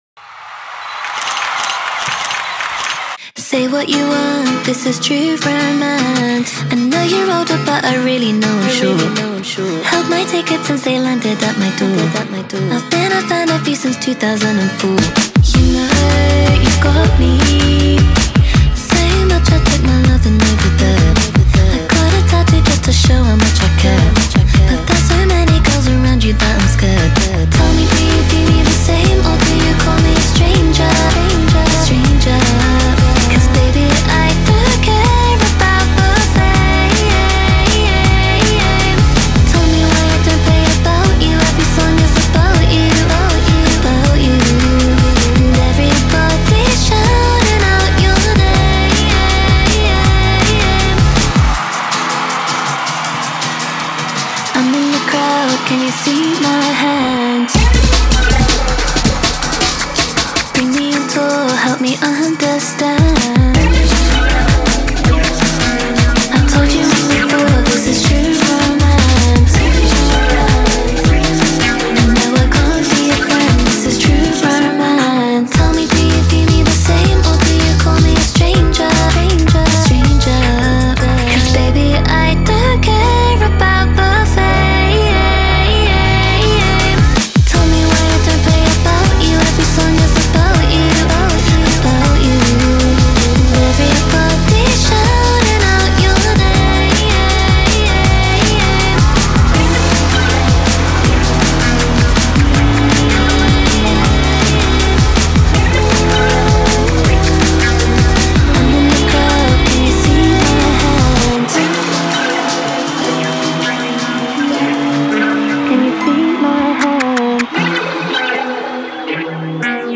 BPM160-160
Audio QualityPerfect (High Quality)
Full Length Song (not arcade length cut)